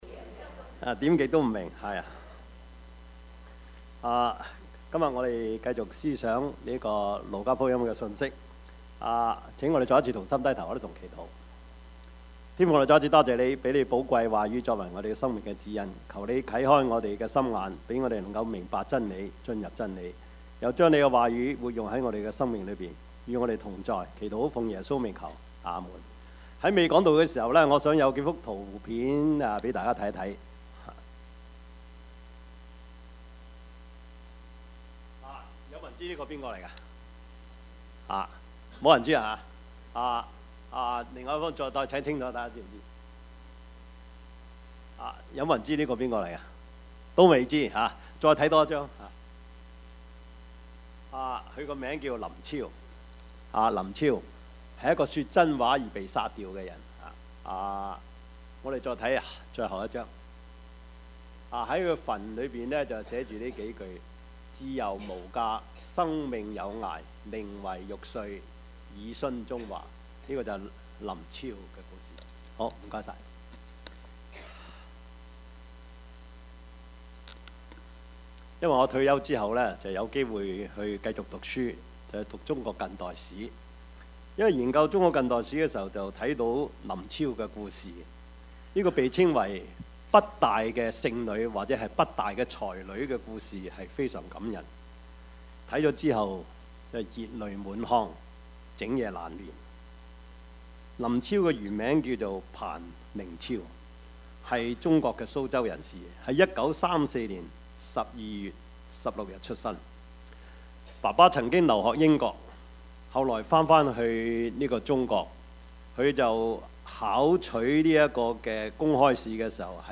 Service Type: 主日崇拜
Topics: 主日證道 « 神蹟奇事 為父為牧 »